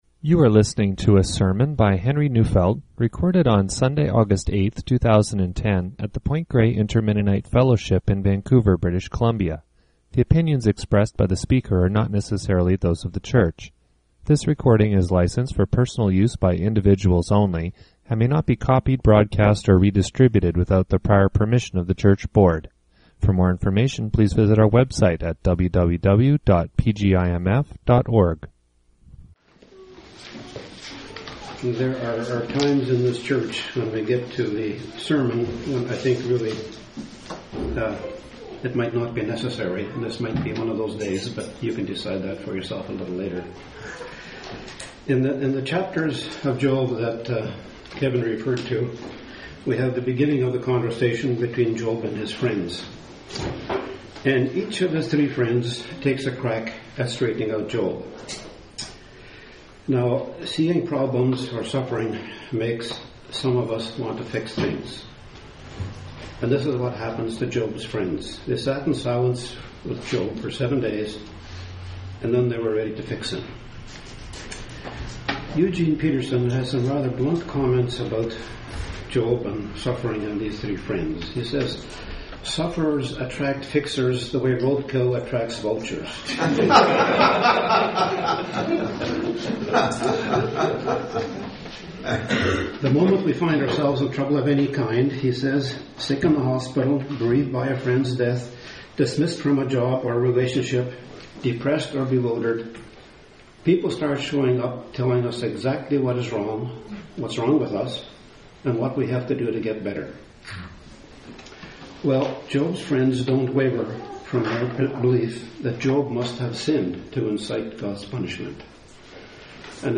Labels: PGIMF sermon discussion